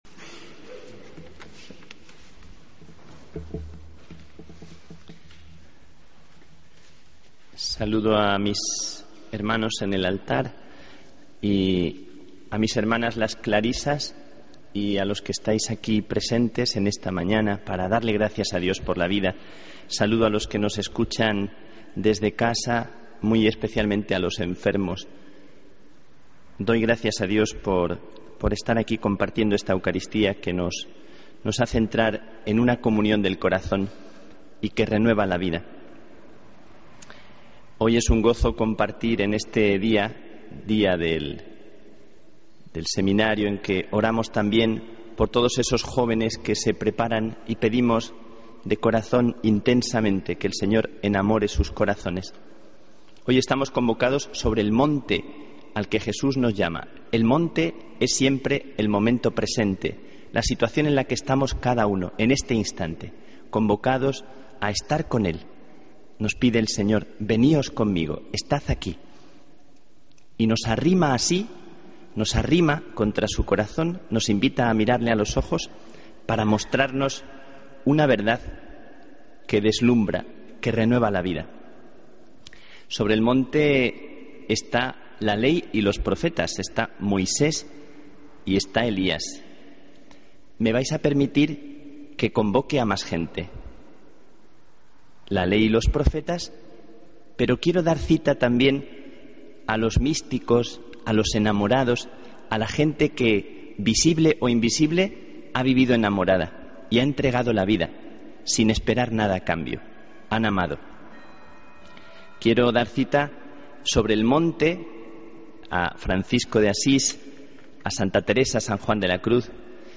Homilía del Domingo 16 de Marzo de 2014